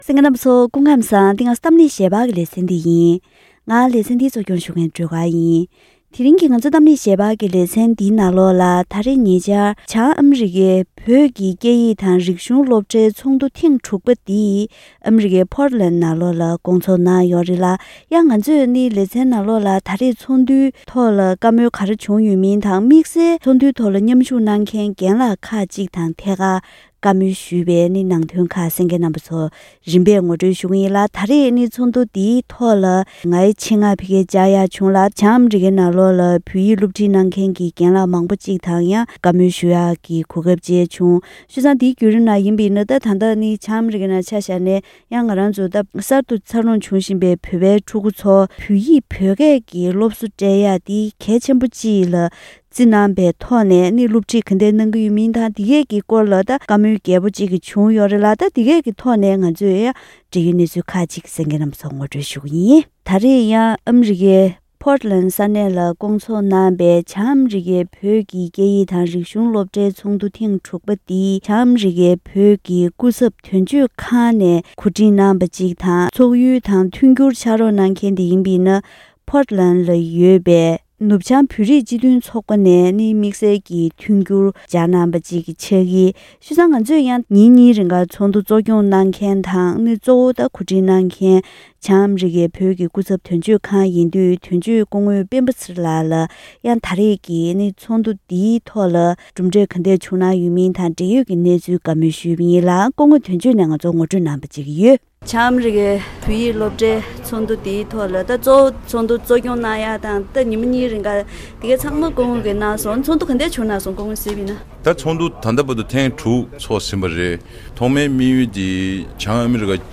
དགེ་རྒན་དང་བཅས་པའི་འབྲེལ་ཡོད་ཁག་ཅིག་ལྷན་བཀའ་མོལ་ཞུས་པ་ཞིག་གསན་རོགས་གནང་།